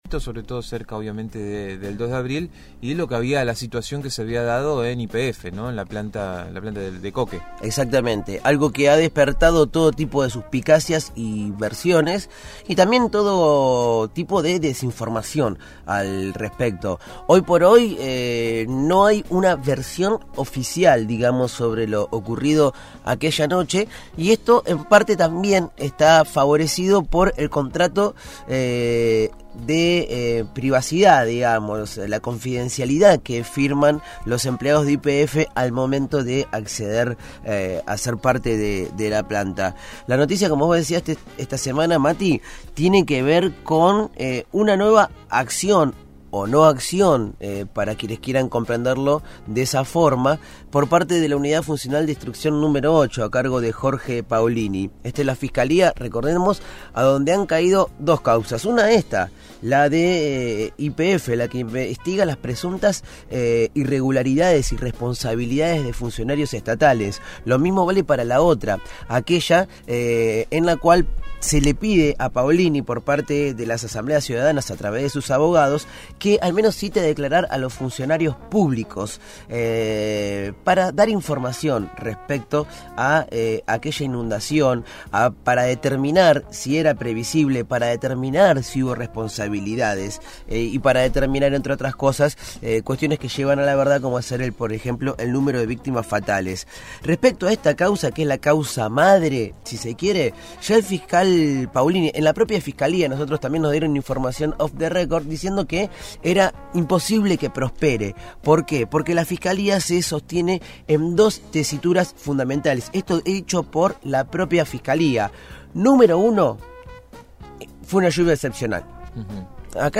dialogó con el equipo de «El Hormiguero» sobre el incendio en la planta de coke de YPF el 2 de abril de 2013, día de la inundación en la ciudad de La Plata, y el pedido del fiscal Jorge Paolini de archivar la causa que investiga las responsabilidades en el hecho.